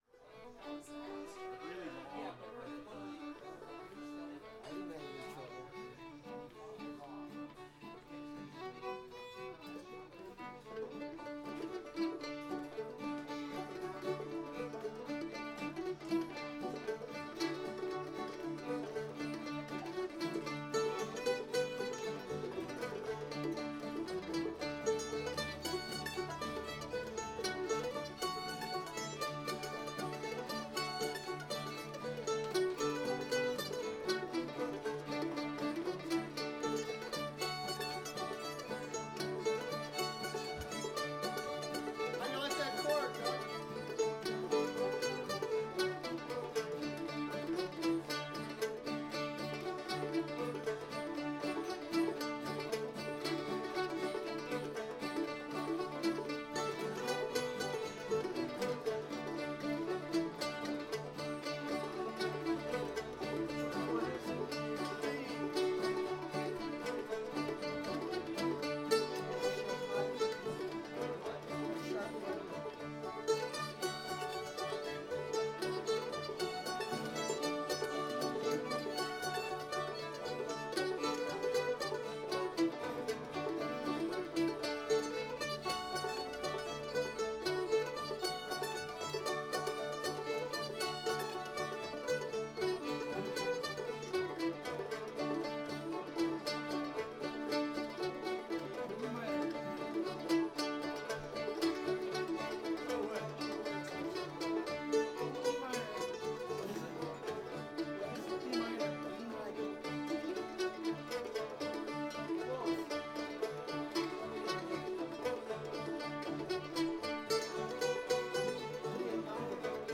five miles from town [D]